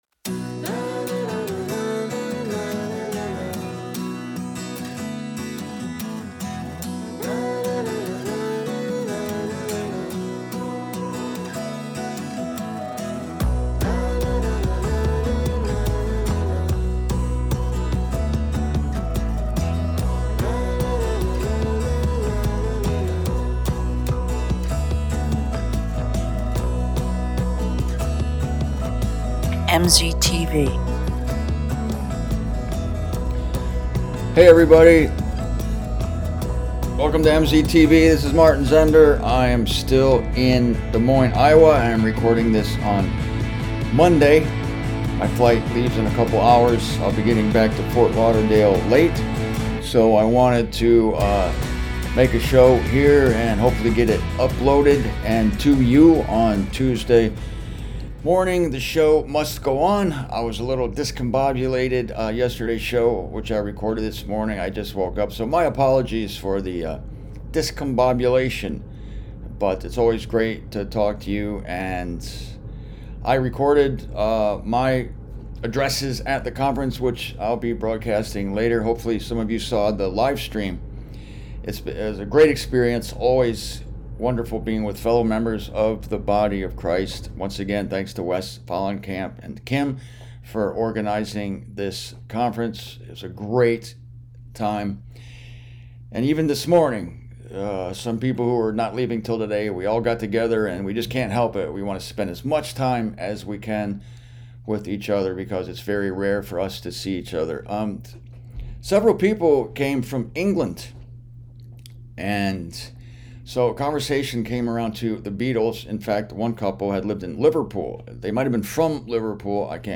I recorded this in my hotel room in Des Moines, Iowa, the day after the conference.